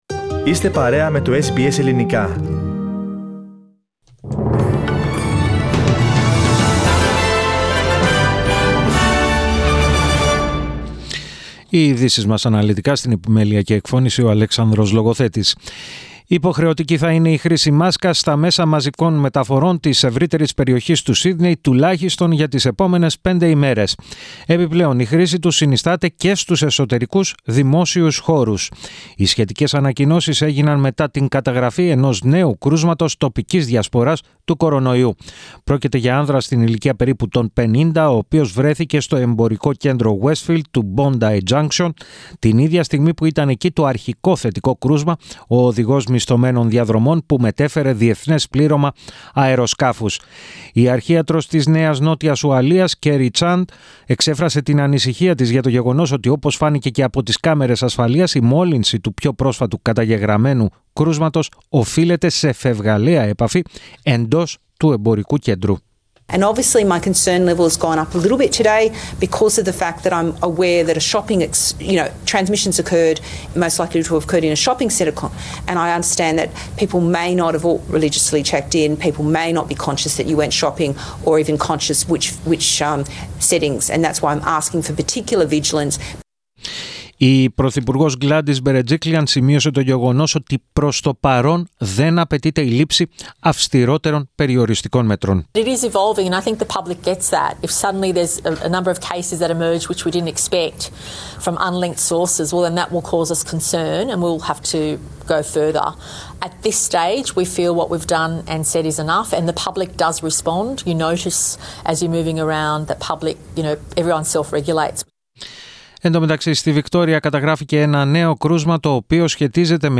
Δελτίο Ειδήσεων 18.06.21